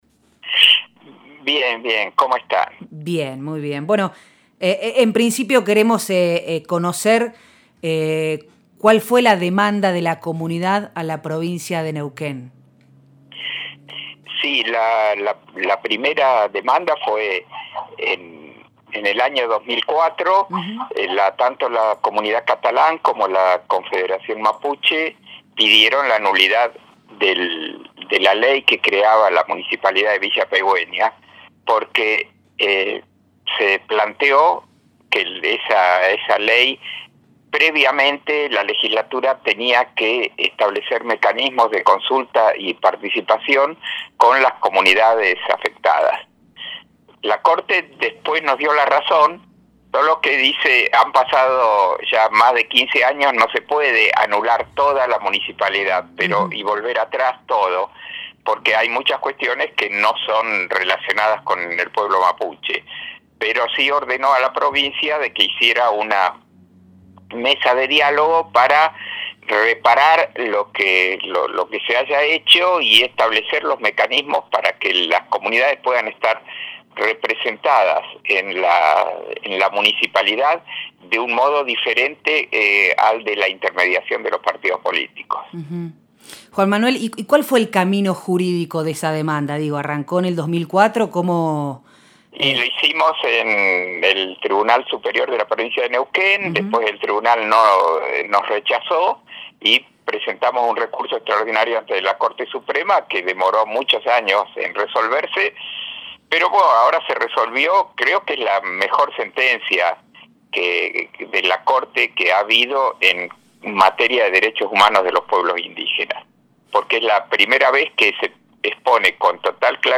Nosotres les Otres, lunes de 18 a 20 por FM Horizonte 94.5; miércoles de 20.30 a 22.30 por FM Del Barrio, 98.1